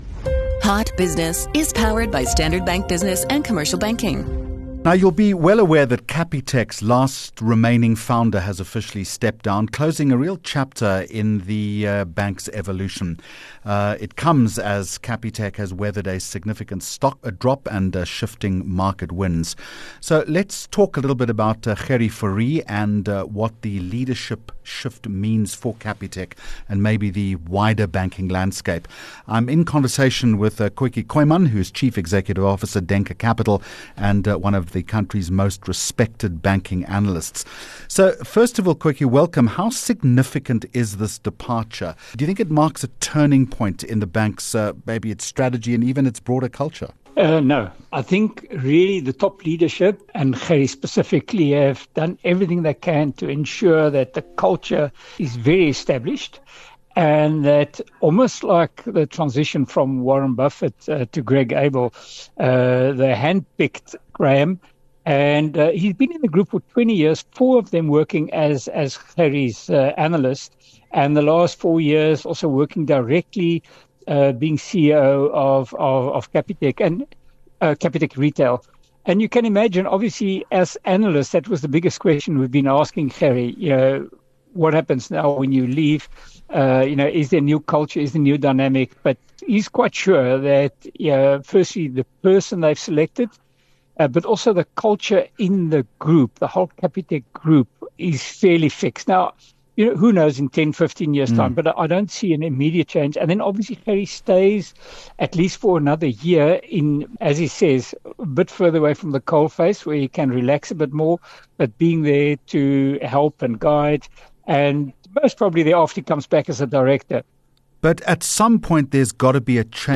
22 Jul Hot Business Interview